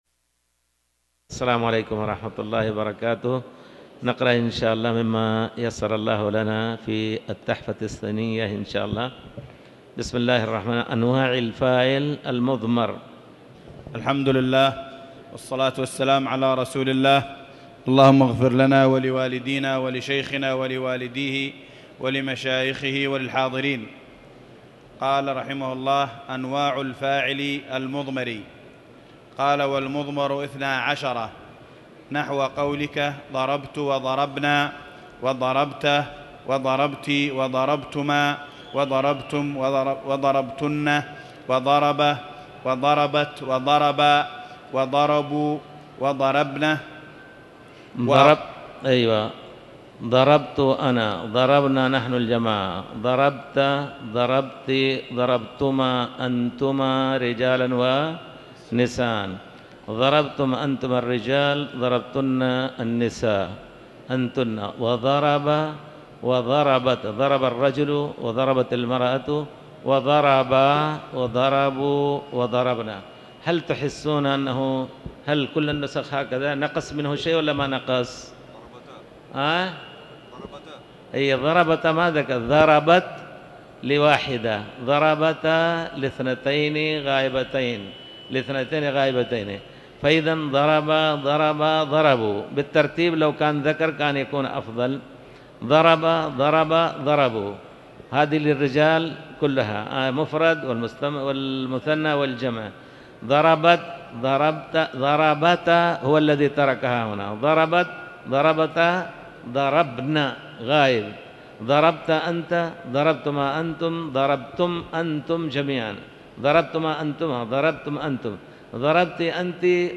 تاريخ النشر ١٩ محرم ١٤٤٠ هـ المكان: المسجد الحرام الشيخ